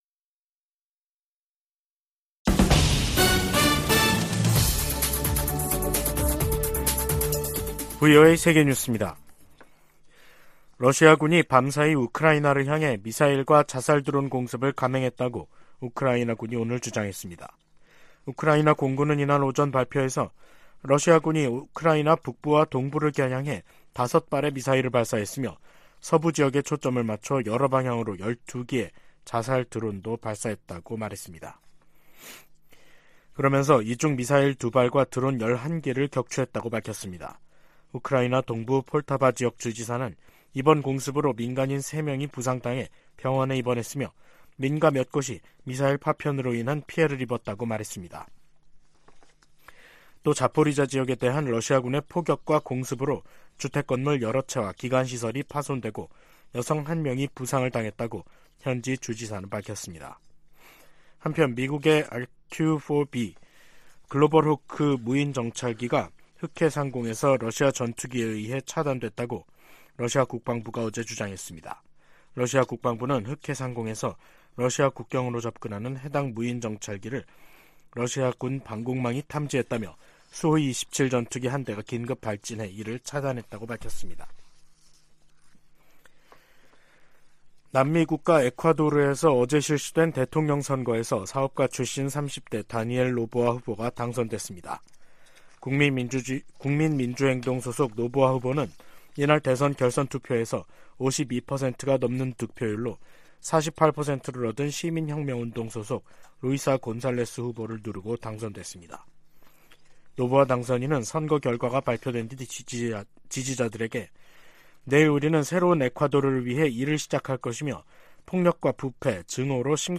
VOA 한국어 간판 뉴스 프로그램 '뉴스 투데이', 2023년 10월 16일 2부 방송입니다. 북한이 지난달 컨테이너 1천개 분량의 군사장비와 탄약을 러시아에 제공했다고 백악관이 밝혔습니다. 미국 정부가 북러 무기 거래 현장으로 지목한 항구에서 계속 선박과 컨테이너의 움직임이 포착되고 있습니다. 줄리 터너 미 국무부 북한인권특사가 한국을 방문해 북한 인권 상황을 개선하기 위해 국제사회가 힘을 합쳐야 한다고 강조했습니다.